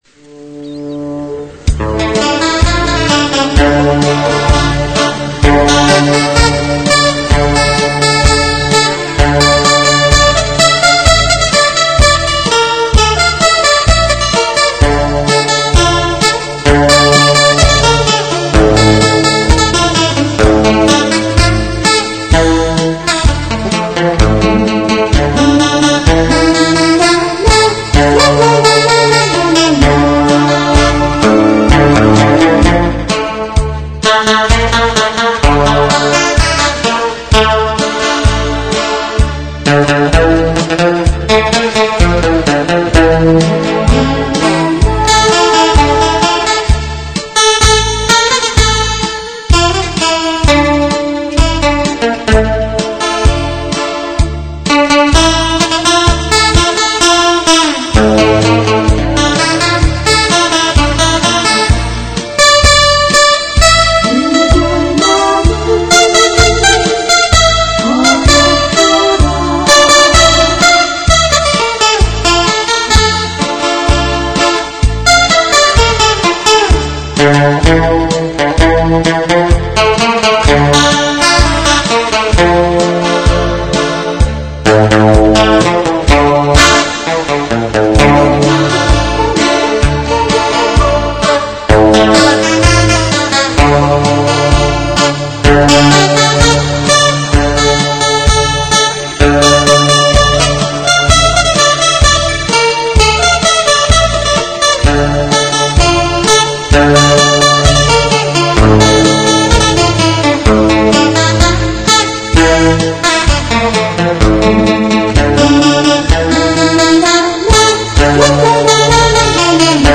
기타연주 모음